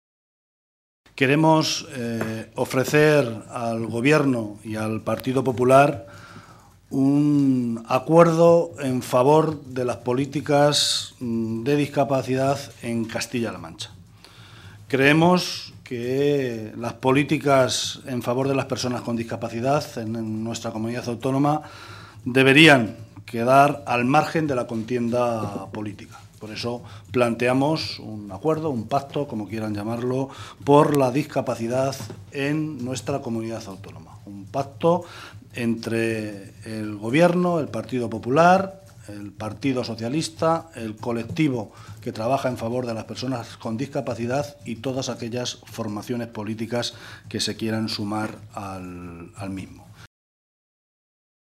Guijarro se pronunciaba de esta manera esta mañana, en Toledo, en una comparecencia ante los medios de comunicación en la que avanzaba las intenciones de los socialistas en el contexto de la tramitación parlamentaria de la Ley de de Garantías y Derechos de las personas con discapacidad.